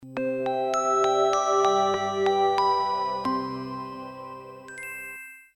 bootsound.mp3